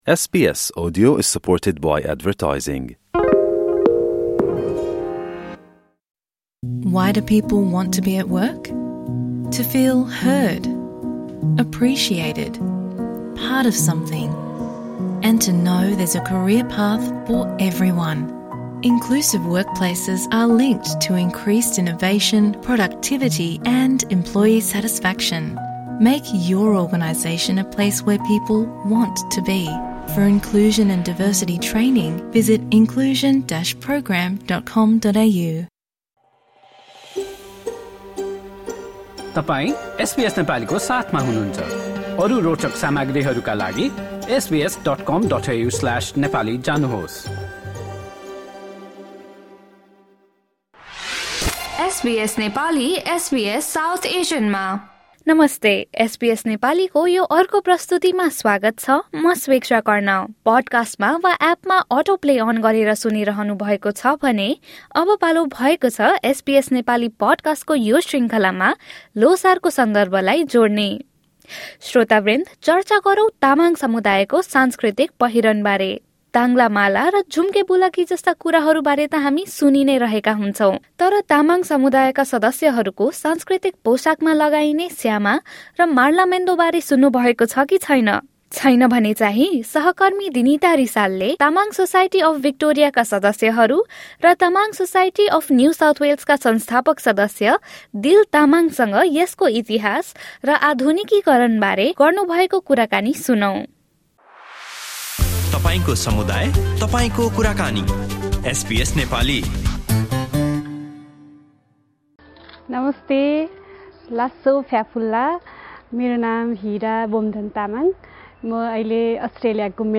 Melbourne's Tamang community recently celebrated Sonam Lhosar, the Lunar New Year, with traditional attire. Members of the Tamang Community spoke to SBS Nepali about elements of traditional Tamang attire and its evolution.